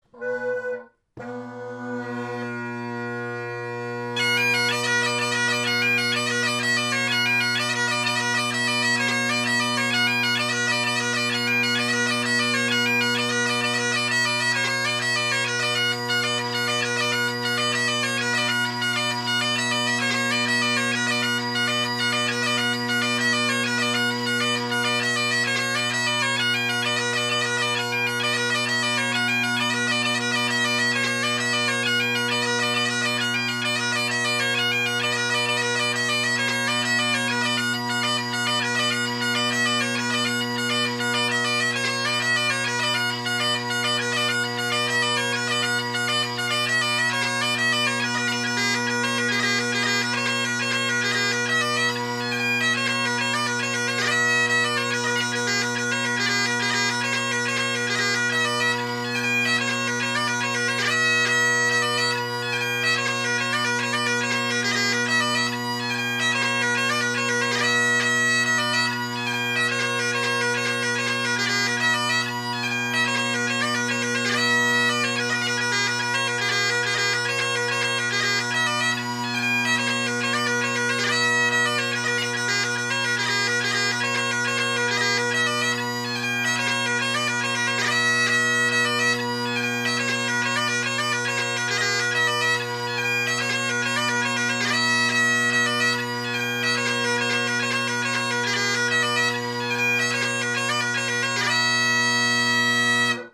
Great Highland Bagpipe Solo
My drones here are obviously still tuned to low A and I’m not using a Bagad chanter.